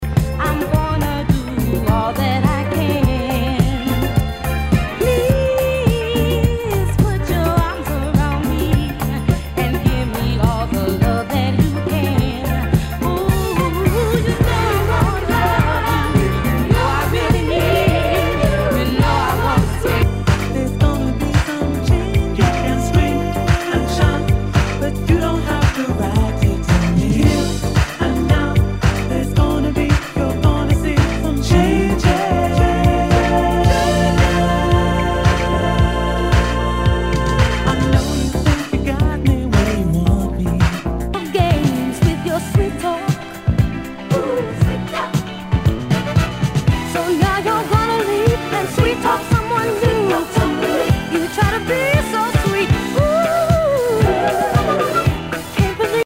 SOUL/FUNK/DISCO
ナイス！ダンス・クラシック！
全体にチリノイズが入ります。